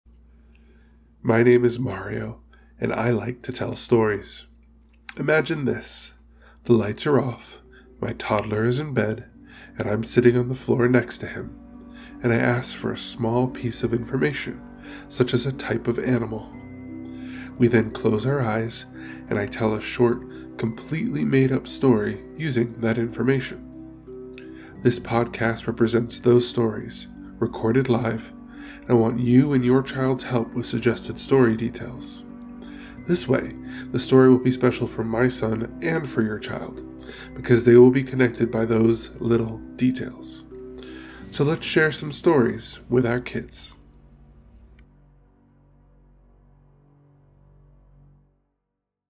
Short, improvised stories from a father to his toddler, using your suggestions to create the next adventure!
Genres: Comedy, Improv, Kids & Family, Stories for Kids